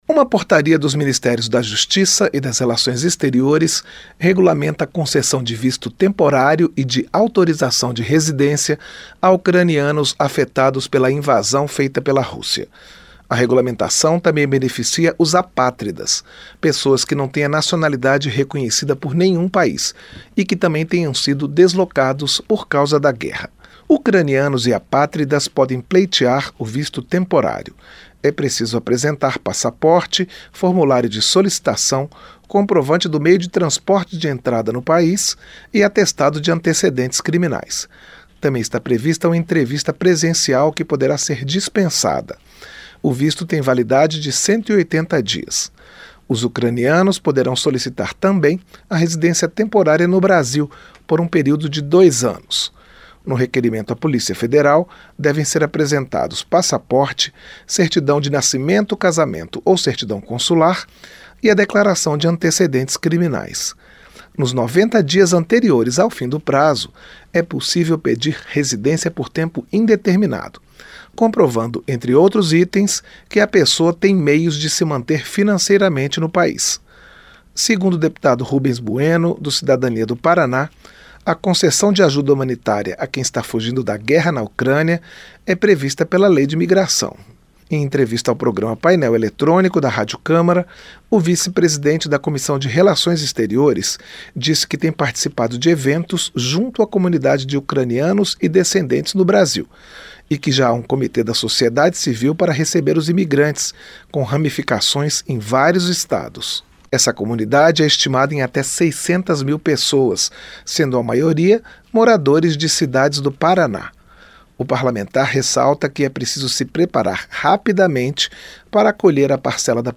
O 1º vice-presidente da Comissão de Relações Exteriores e de Defesa Nacional da Câmara dos Deputados, Rubens Bueno (Cidadania-PR), disse, em entrevista à Rádio Câmara, que tem participado de eventos junto à comunidade de ucranianos e descendentes no Brasil e que já há um comitê da sociedade civil para receber os imigrantes, com ramificações em vários estados.